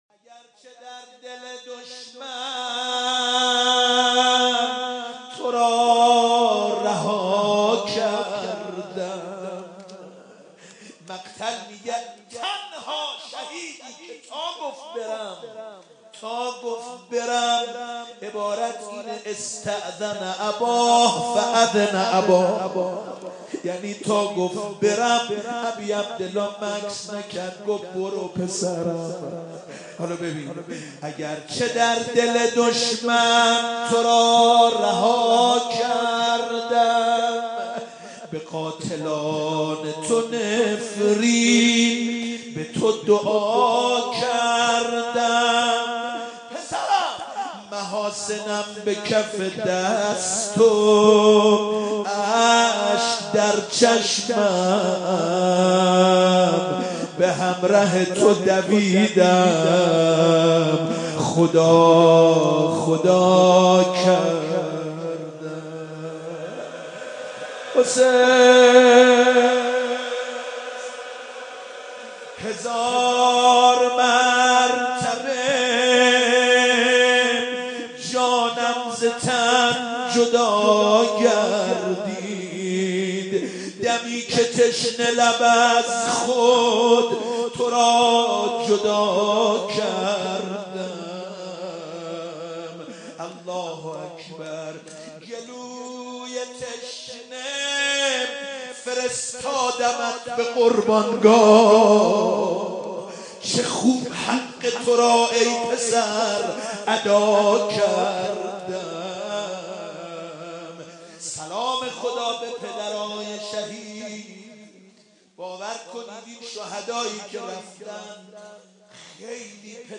روضه حضرت علی اکبرعلیه السلام